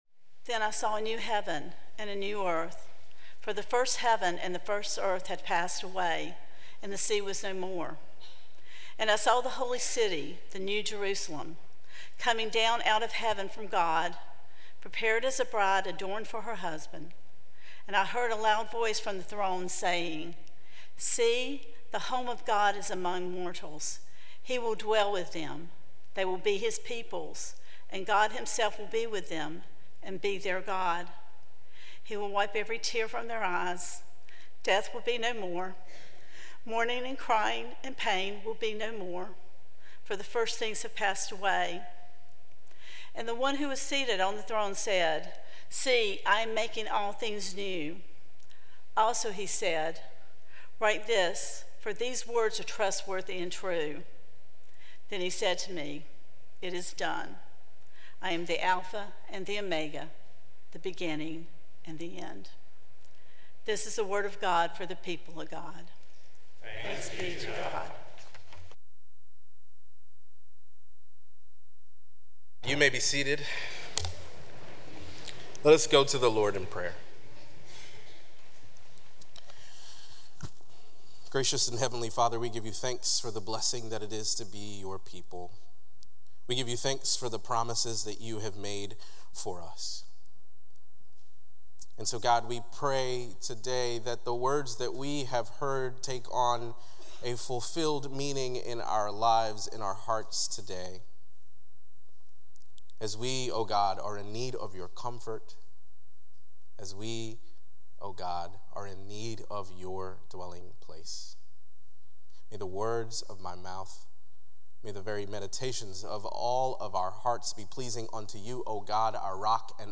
Sermon Reflections: How can we, as individuals and as a church community, leave a lasting legacy that reflects our faith and values for future generations?